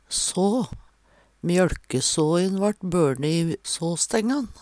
så - Numedalsmål (en-US)